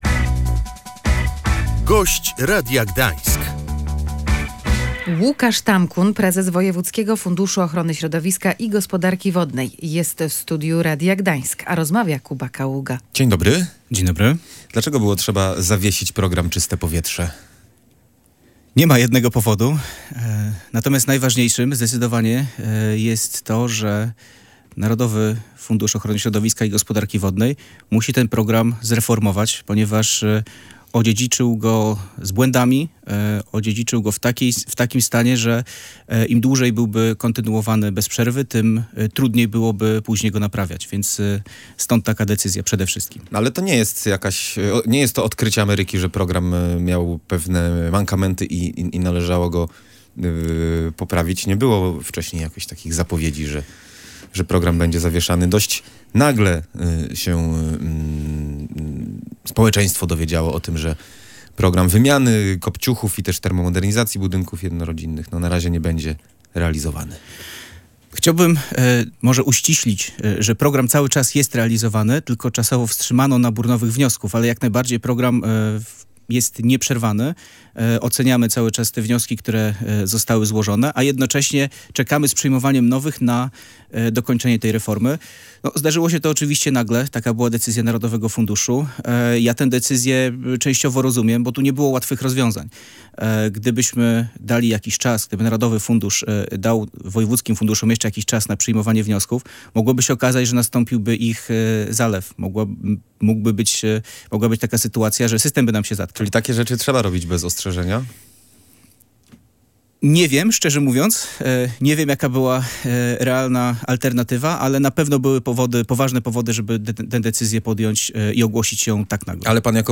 „Gość Radia Gdańsk” – Łukasz Tamkun Naszym gościem jest Łukasz Tamkun, prezes zarządu Wojewódzkiego Funduszu Ochrony Środowiska i Gospodarki Wodnej w Gdańsku.